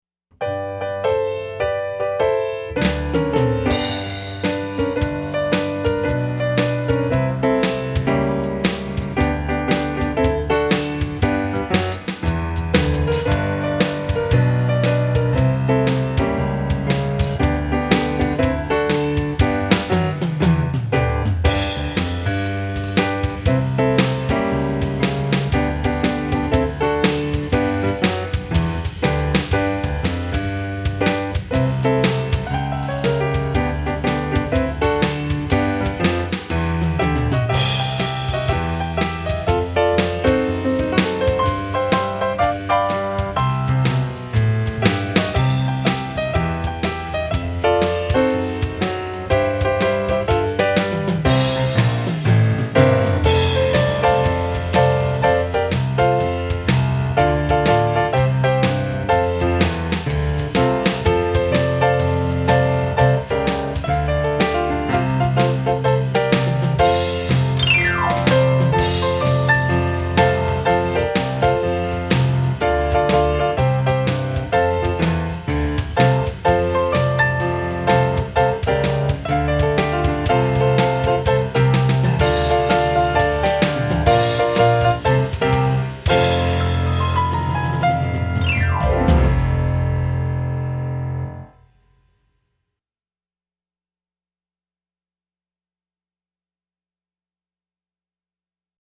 Country Gospel, Worship Piano Solos
DIGITAL SHEET MUSIC -PIANO SOLO